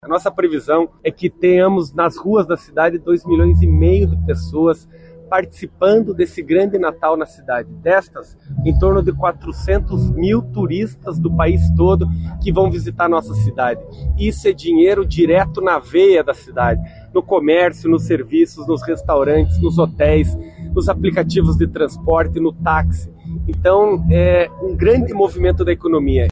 O prefeito Eduardo Pimentel (PSD), disse que a previsão é de que o final de ano deve atrair mais turistas para Curitiba.